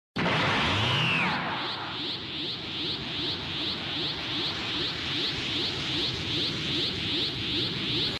Anime Powerup Sound Effect Free Download
Anime Powerup